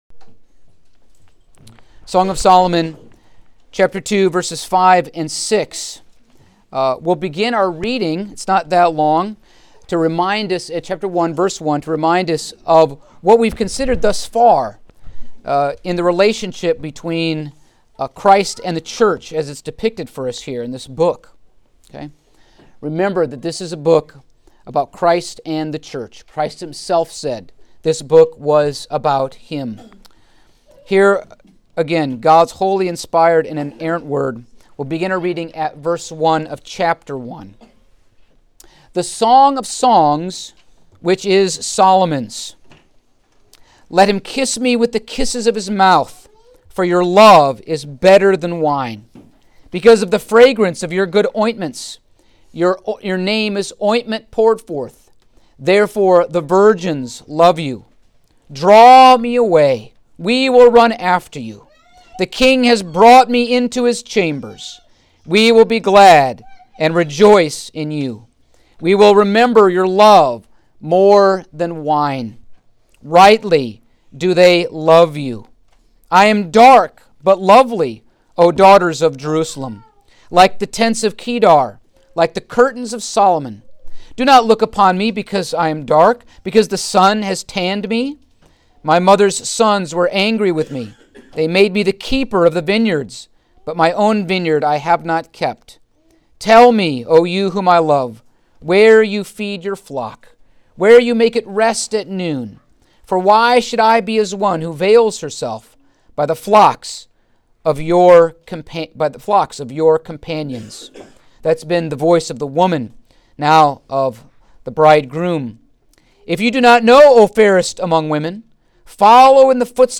The Gospel of John Passage: Song of Solomon 2:5-6 Service Type: Sunday Afternoon Topics